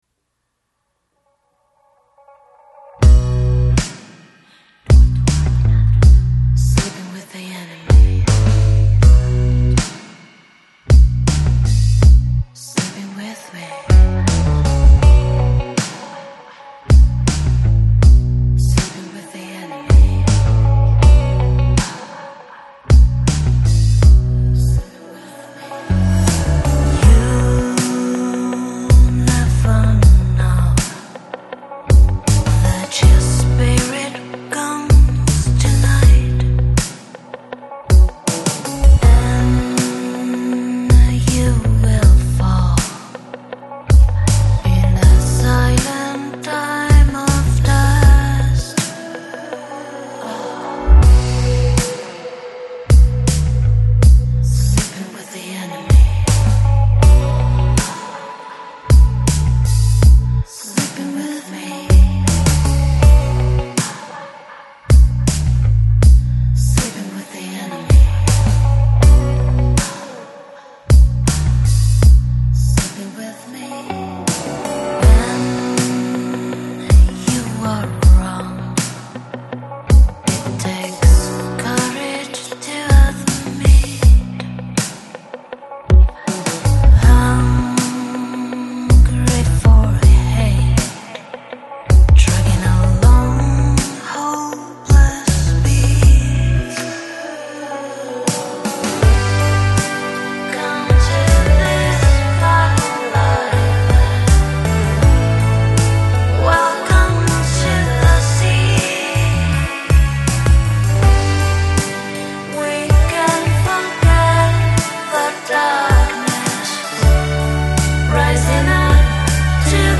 Жанр: Lounge, Pop, Jazz